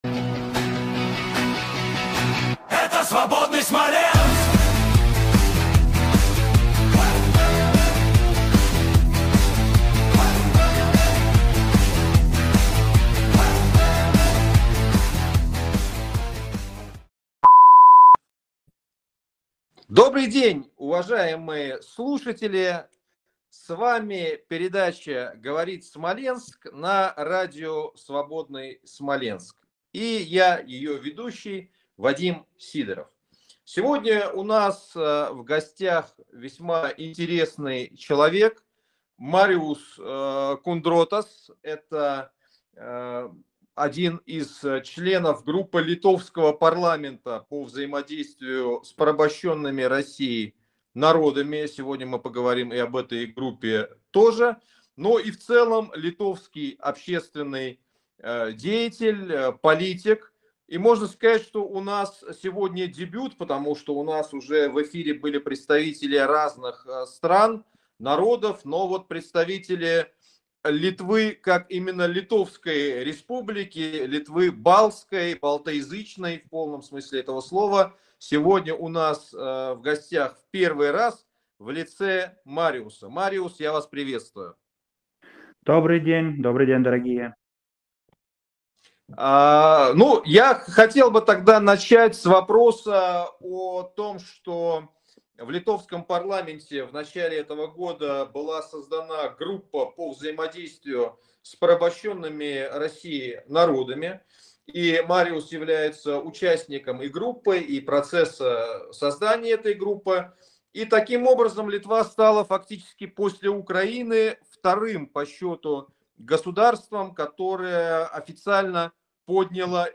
Гостем очередной передачи «Говорит Смоленск» на радио «Свабодный Смаленск» стал литовский политик